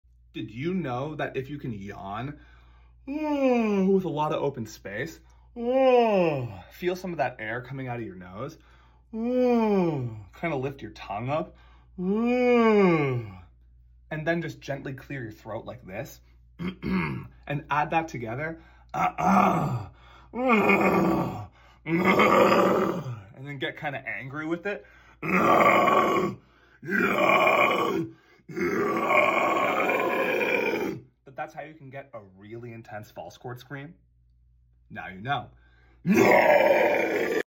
Use this to learn a mean False Cord Scream!